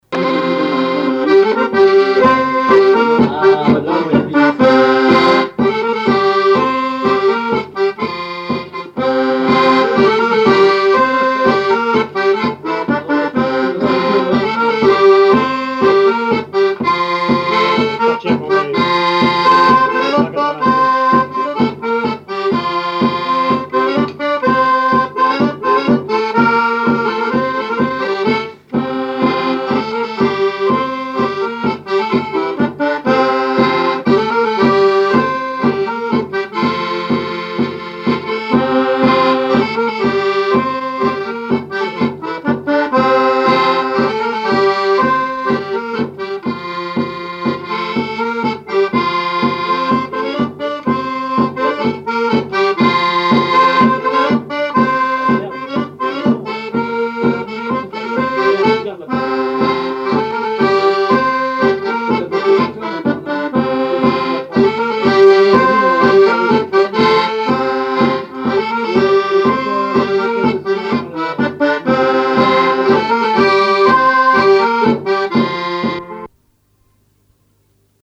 Saint-Hilaire-de-Riez
répertoire à l'accordéon chromatique et grosse caisse
Pièce musicale inédite